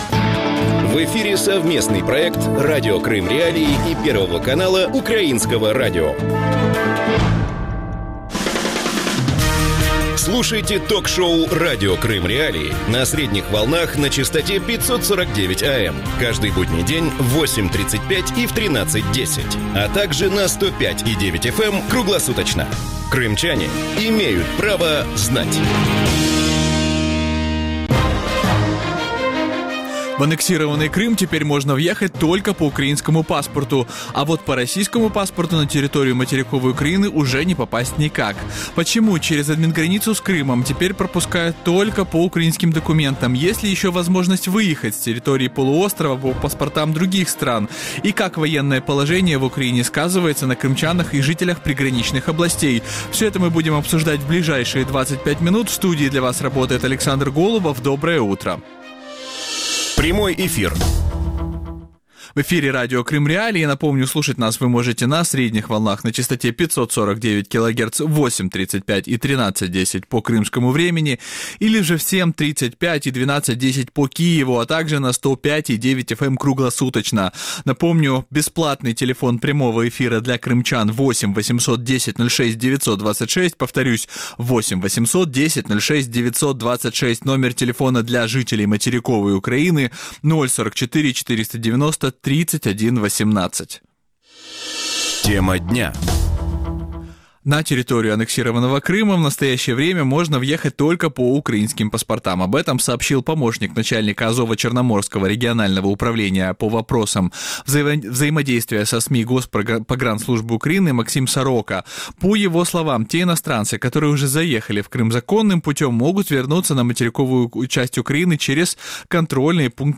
И как военное положение в Украине сказывается на крымчанах и жителях приграничных областей? Гости эфира